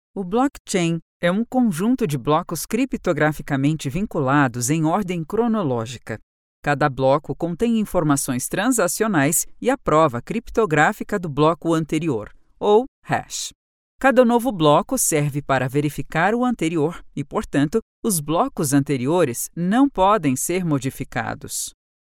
locutora brasil, brazilian voice over